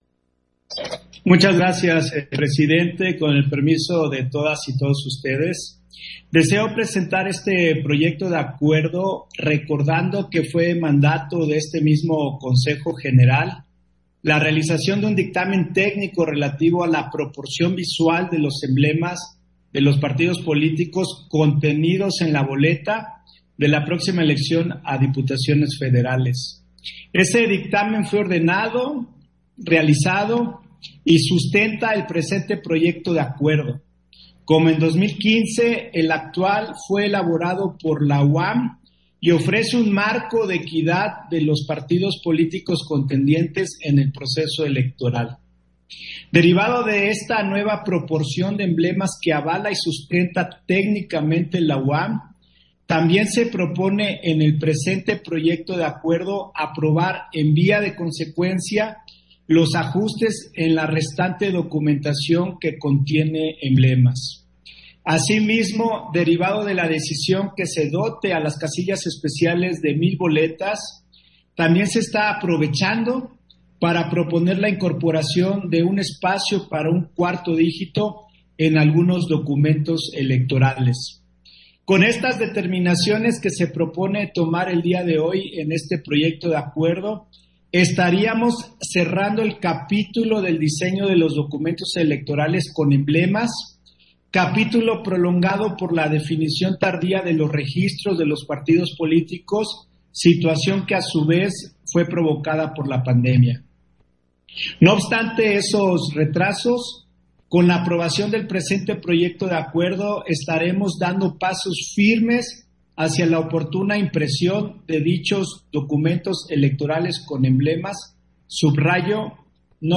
Intervención de José Roberto Ruiz en Sesión Extraordinaria, en el punto en que se aprueba el diseño y ajuste de la documentación electoral para el Proceso Electoral 2021